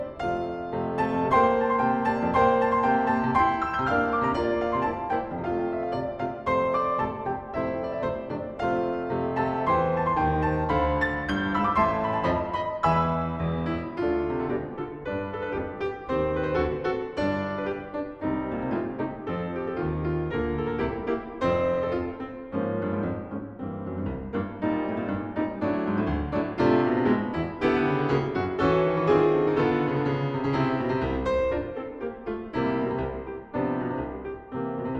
Жанр: Классика
Classical